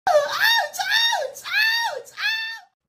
aaouchh Meme Sound Effect
This sound is perfect for adding humor, surprise, or dramatic timing to your content.
aaouchh.mp3